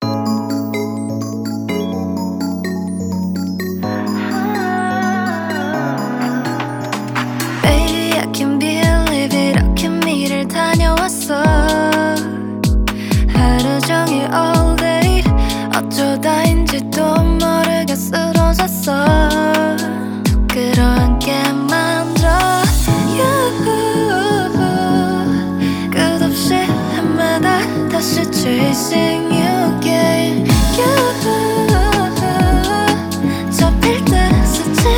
2024-09-25 Жанр: Поп музыка Длительность